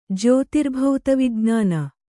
jyōtirbhauta vijn`āna